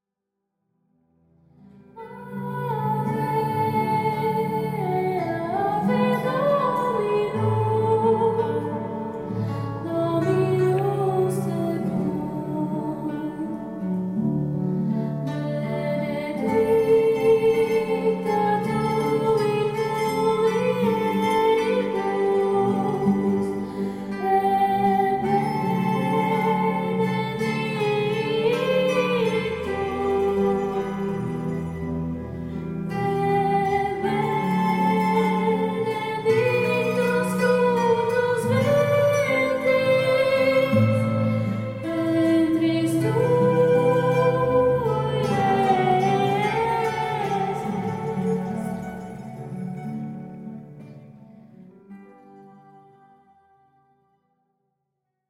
Caracterizamo-nos pela sonoridade jovem e elegante, marcada pelo requinte melódico do violino e pela harmonia suave das vozes.